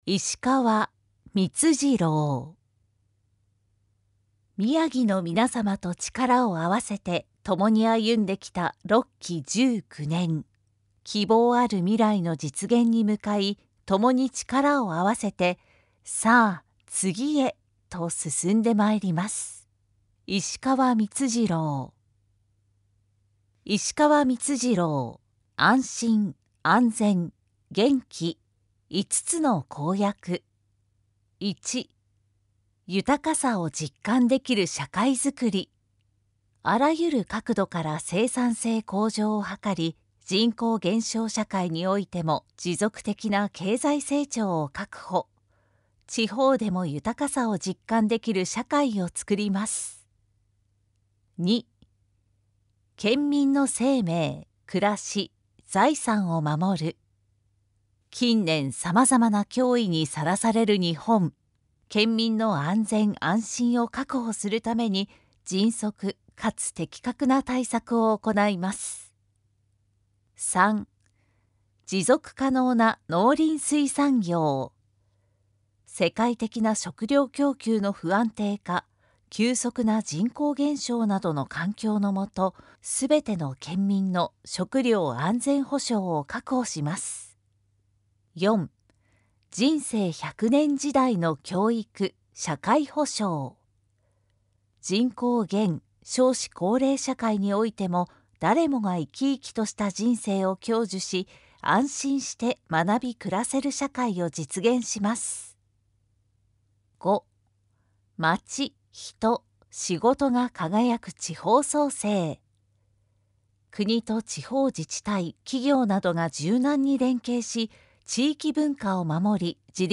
宮城県議会議員補欠選挙（宮城野選挙区）候補者情報（選挙公報）（音声読み上げ用）
選挙公報音声版（MP3：2,743KB）